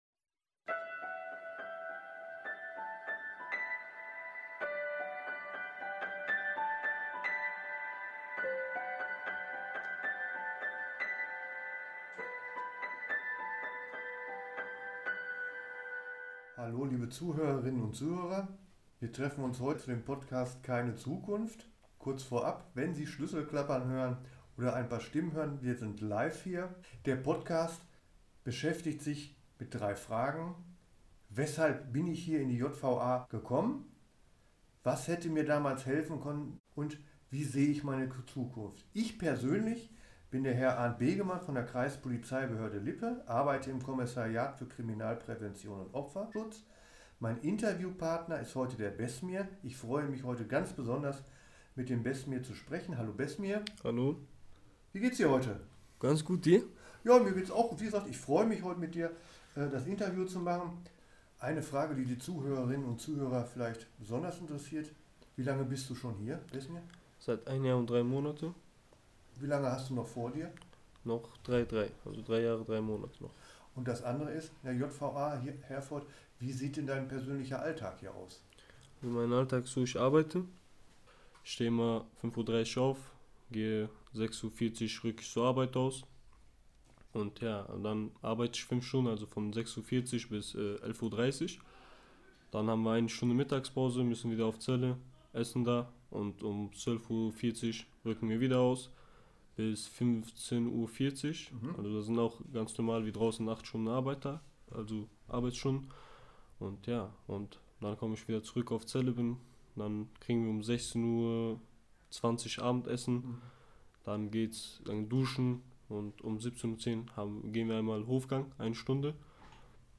Das Interview schildert die persönliche Entwicklung des jugendlichen Straftäters bis zu seiner Festnahme durch die Polizei.